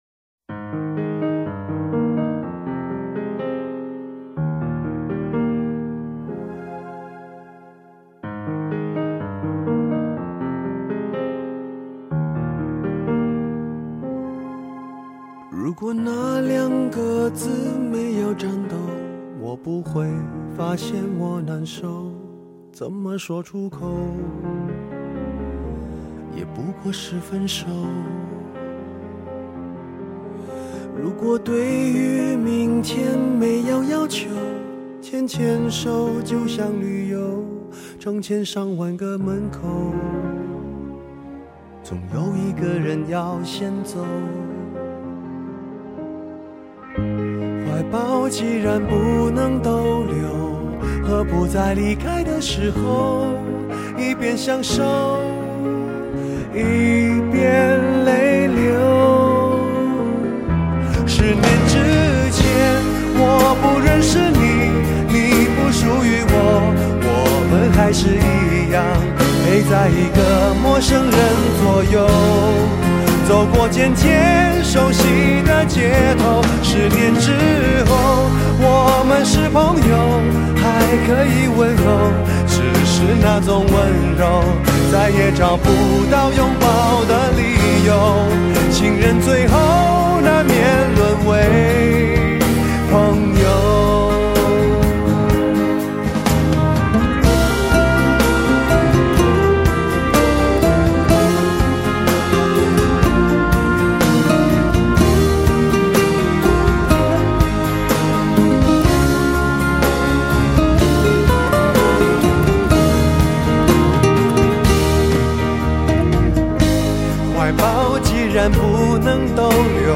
声音温暖 富有磁性
不可抗拒的磁性男声
高密度34bit数码录音